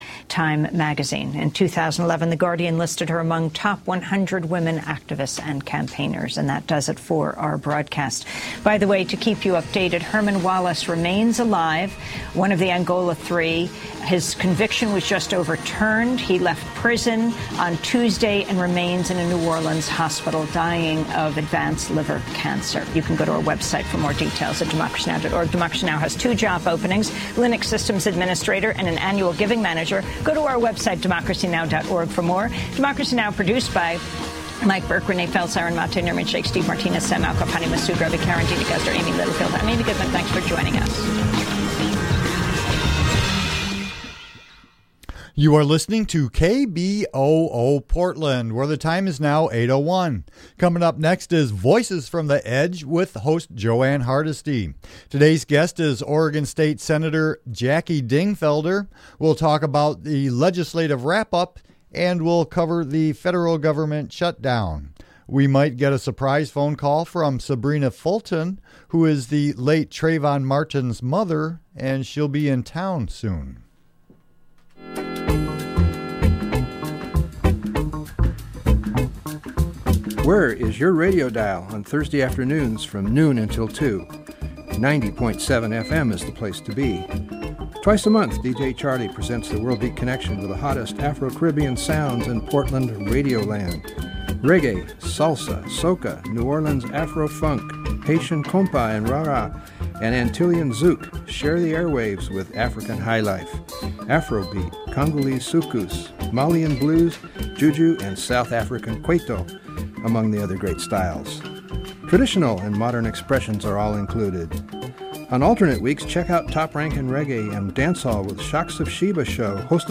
Interview with authors of Dollaroracy Nichols & McChesney
Progressive talk radio from a grassroots perspective